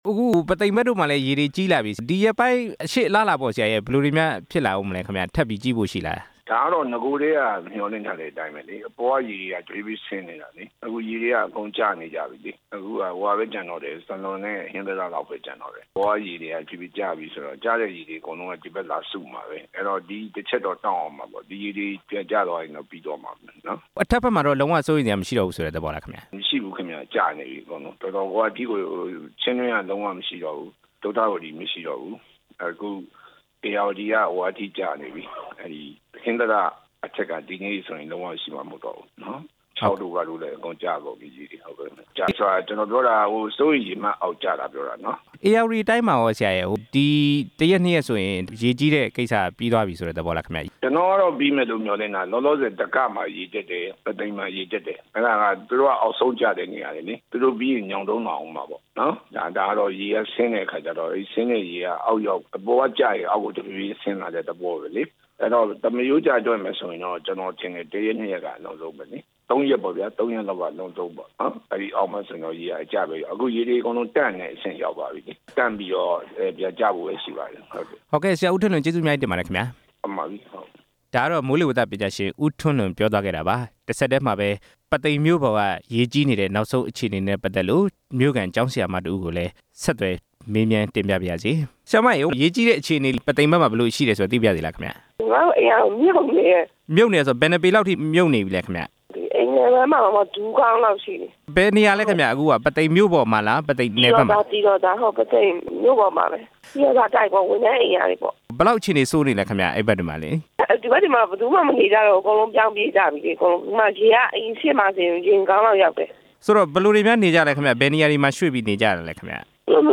ဧရာဝတီတိုင်းက ရေဘေးနောက်ဆုံးအခြေအနေ ဆက်သွယ်တင်ပြချက်